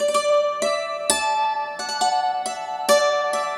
Dulcimer12_134_G.wav